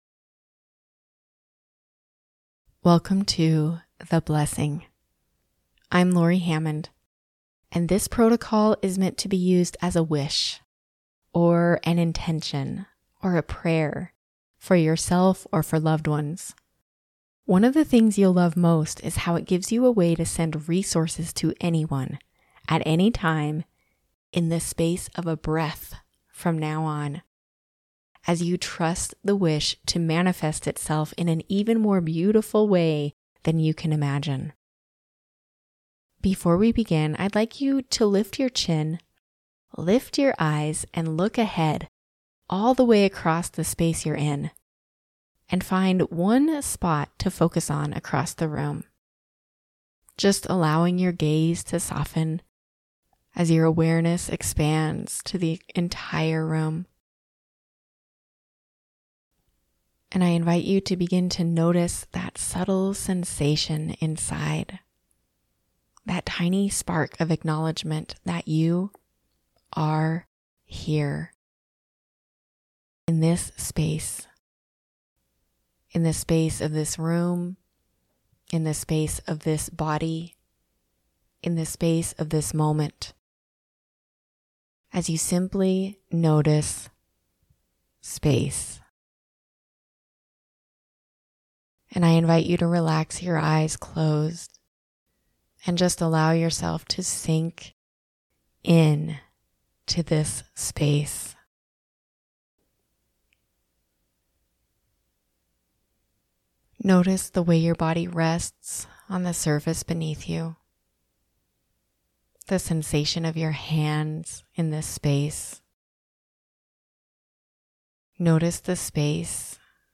A couple weeks ago I created a hypnosis protocol called, " The Blessing ". It's one of my favorite protocols because it gives people an effective way to pray... Or send wishes... In the space of a breath.